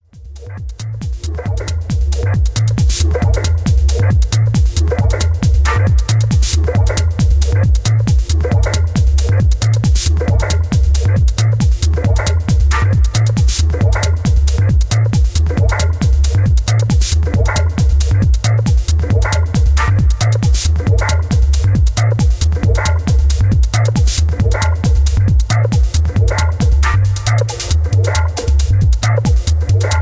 Positive dynamic techno house track